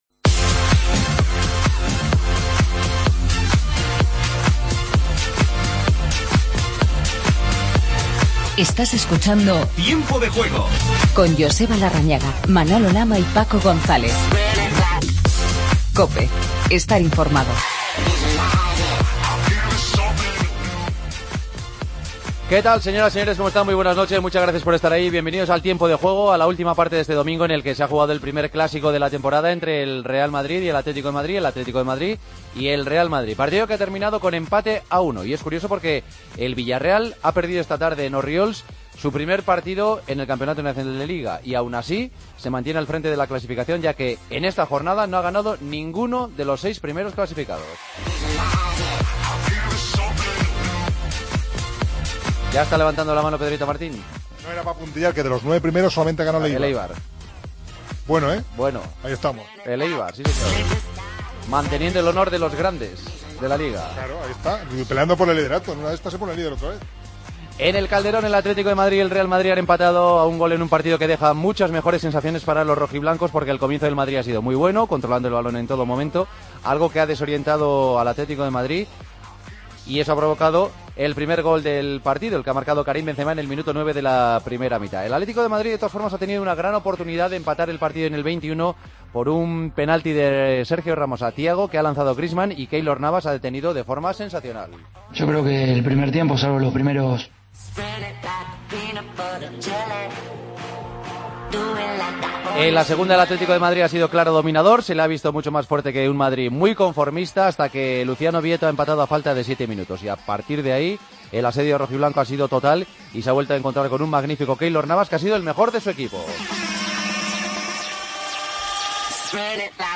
Atleti y Real Madrid empatan a uno en el último partido de la 7ª jornada. Entrevista a Vietto y escuchamos a Benzema y Casemiro....